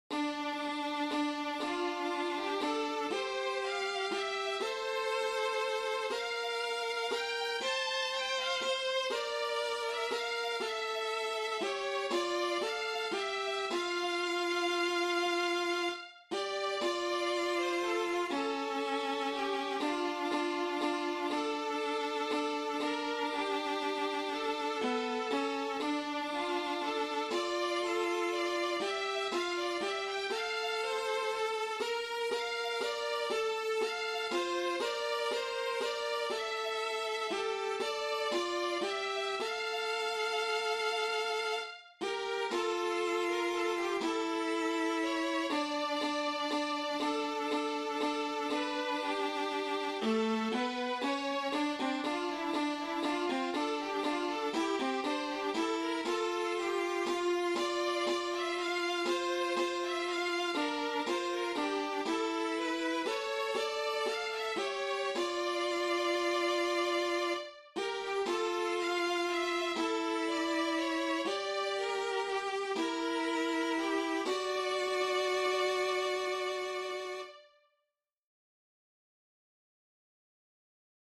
a violin and viola obligato